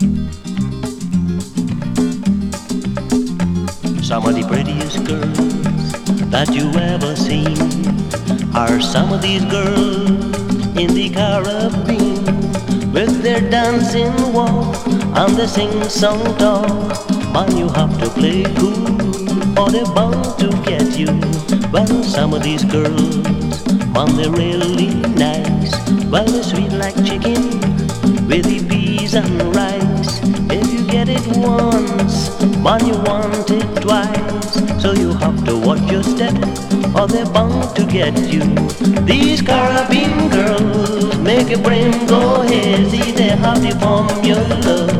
Calypso, Pop, World　Belgium　12inchレコード　33rpm　Stereo